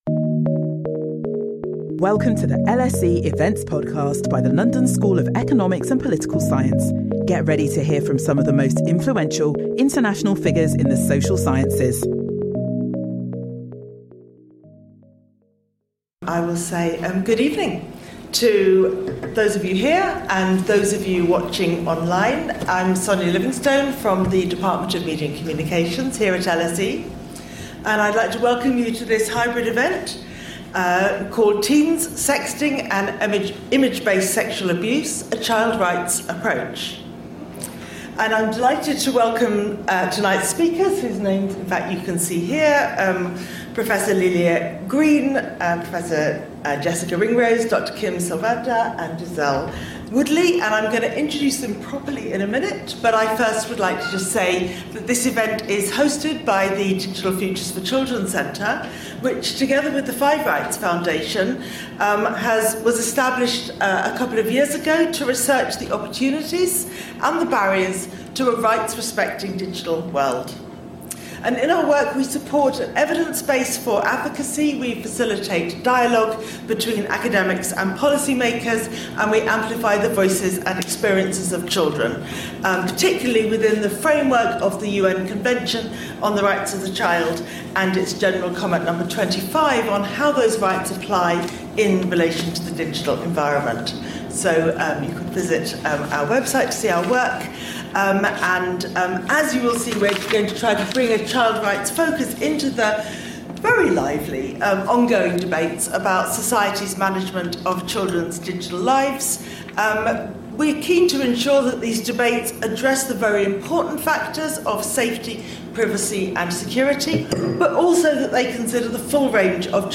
Apart from image-based sexual harassment and abuse, teens also face emerging risks such as AI-informed deepfakes and sextortion. In this public event, four speakers will discuss empirical findings from three different countries: Australia, Sweden and the UK.